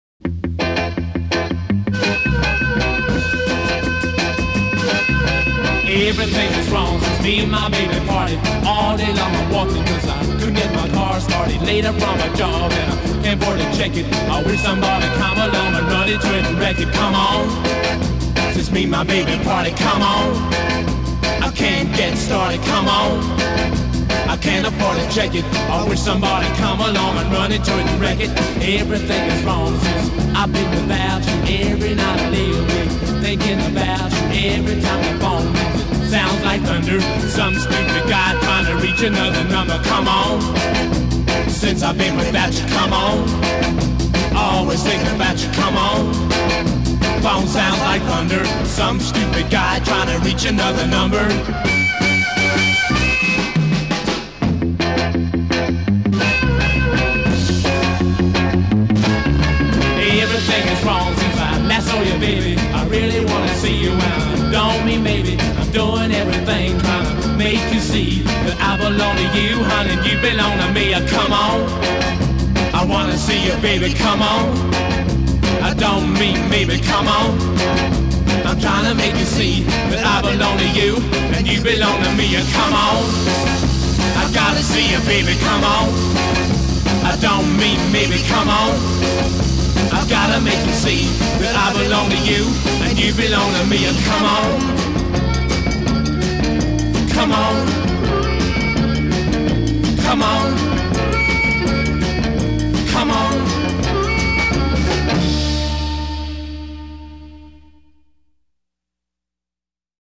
Recorded at Olympic Studios, London; 10 May 1963.
intro 0:00 4 bass & drums, add guitar and harmonica
A verse 0: 8 double-tracked solo vocal a
refrain : 8 2 & 3 part harmony b
motif played twice, second time key shifts up
A verse : 8 double-tracked vocal increasingly out of phase e
" : 8 drums shift to cymbals f
coda : 8 drums to cowbell; repeat hook g